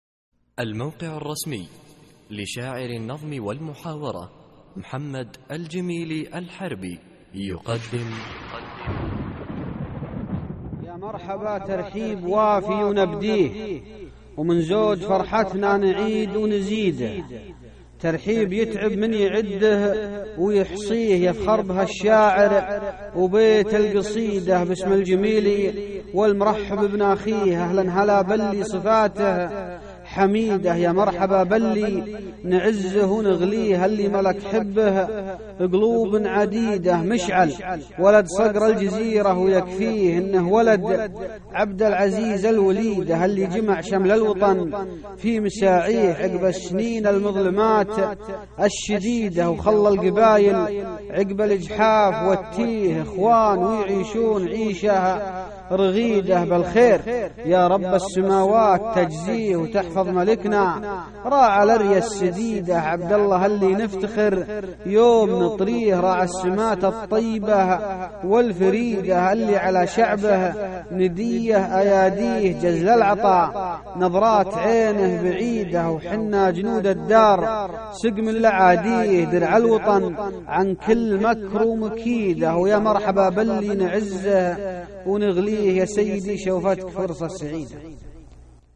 عرض القصيدة :شوفتك فرصة سعيدة ~ إلقاء
القصـائــد الصوتية